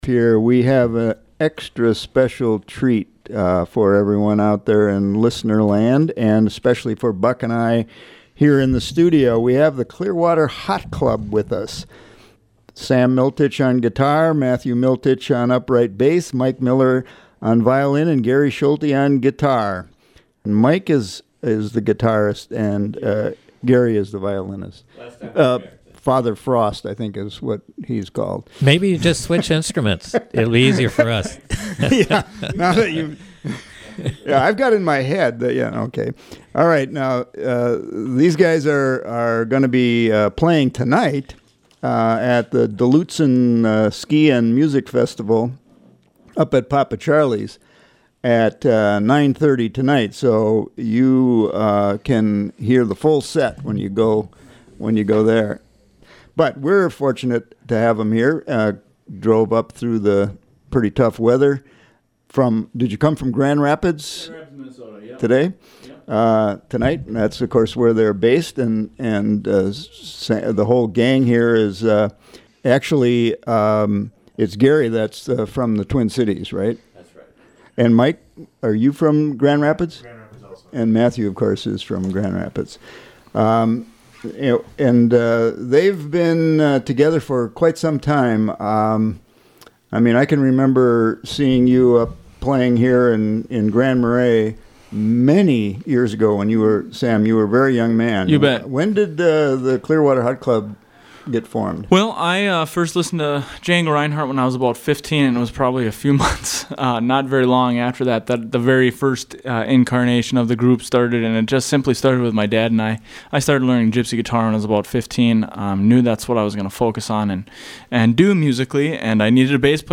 hot jazz on a cold night